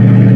engine5.ogg